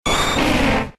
Cri d'Herbizarre K.O. dans Pokémon X et Y.